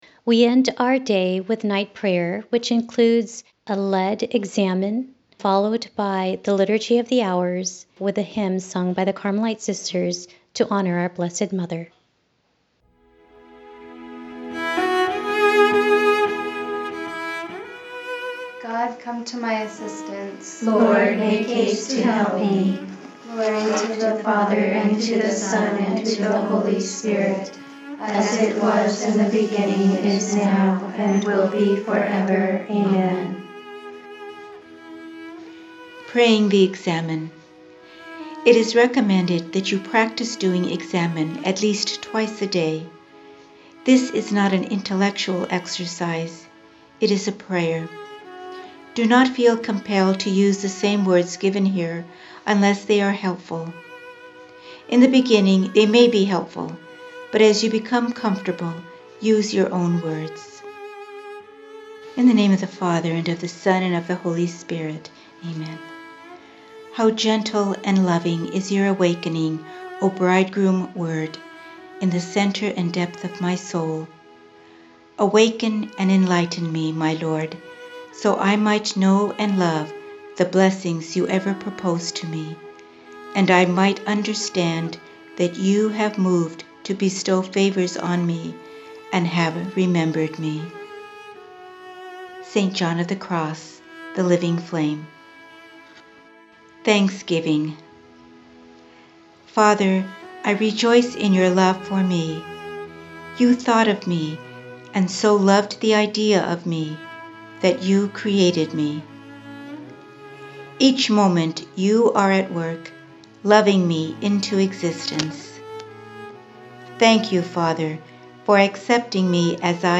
Night prayer includes a briefly led Examen, followed by recitation of the Liturgy of the Hours, and a hymn sung to our Lady.